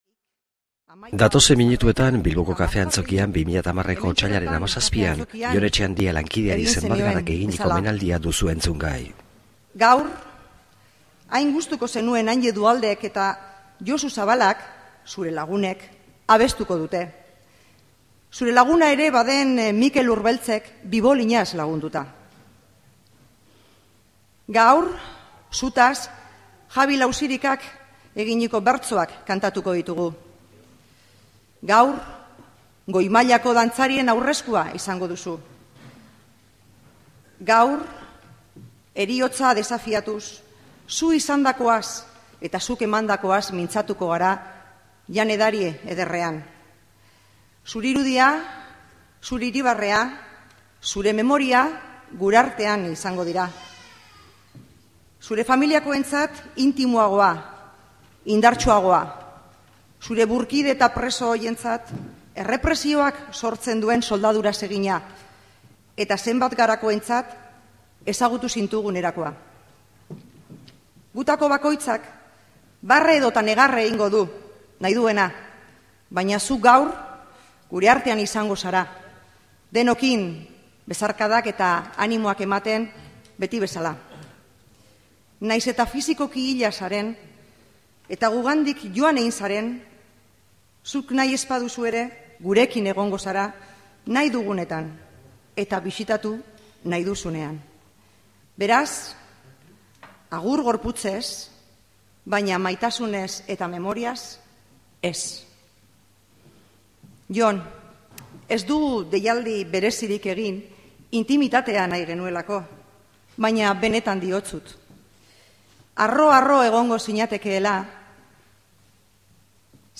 Bere omenez, Zenbat Gara elkarteak ekitaldi xumea prestatu eta hilaren 17an gauzatu ere egin zuen, Bilboko Kafe Antzokian.
poemak irakurriz eta kantak zein dantzak eskainiz